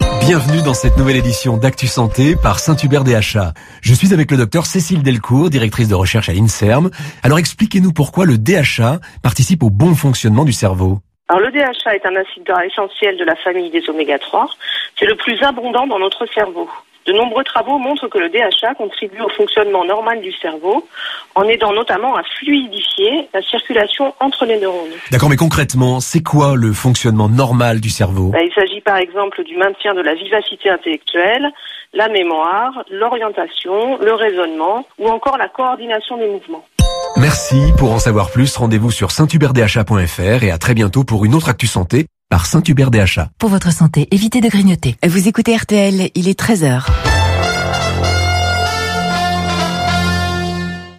Genre : Voix-off